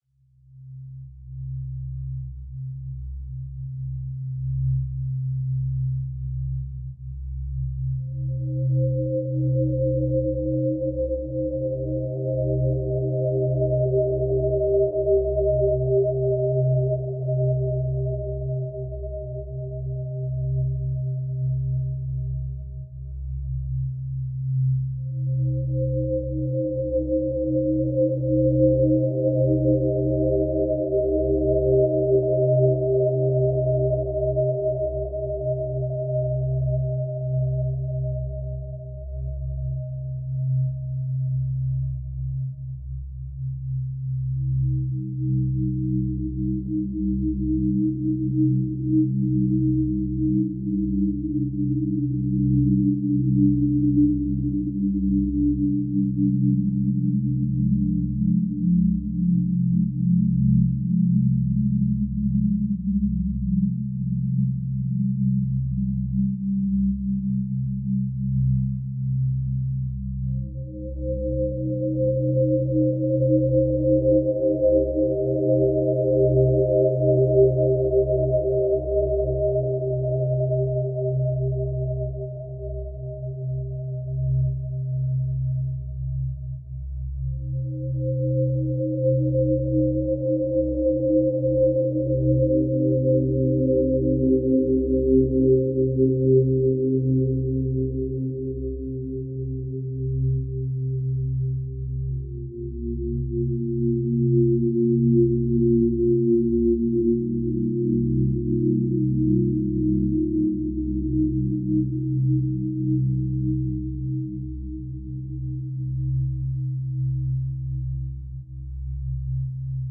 dreamy nostalgic